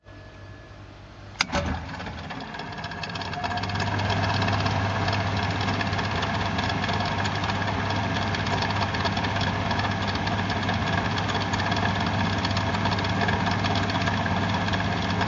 水冷却器
描述：办公室水冷却器在工作时的记录。也可以用于冰箱的噪音。
Tag: 冰柜 冰箱 水冷却器 电冰箱